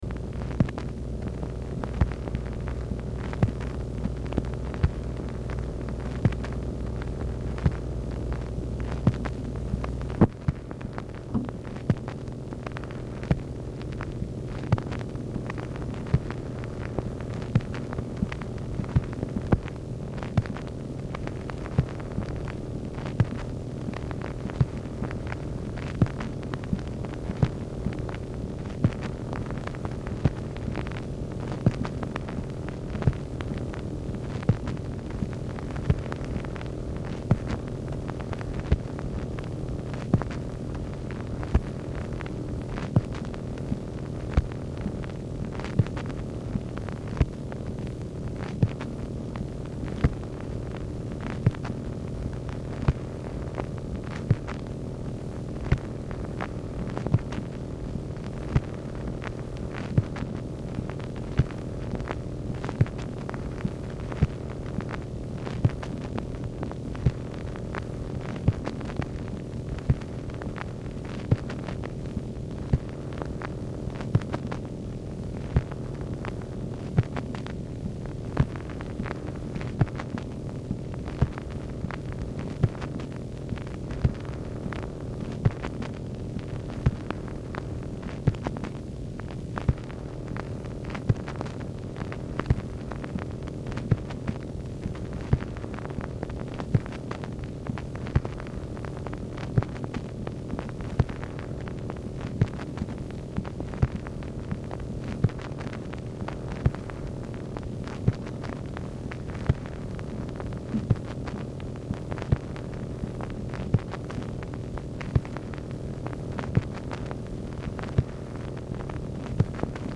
Telephone conversation
MACHINE NOISE
ORIGINAL BELT DAMAGED
Format Dictation belt